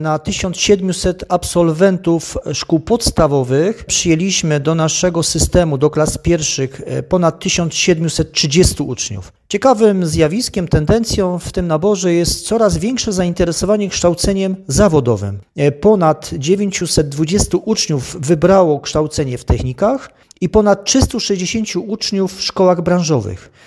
To dowód na to, że coraz więcej osób chce inwestować w swoją przyszłość poprzez nabywanie uprawnień i kompetencji zawodowych. Powiat od 8 lat jako priorytet traktuje szkolnictwo zawodowe, mówi wicestarosta żywiecki Stanisław Kucharczyk.